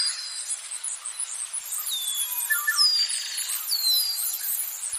Bee Buzzing
Bee Buzzing is a free nature sound effect available for download in MP3 format.
468_bee_buzzing.mp3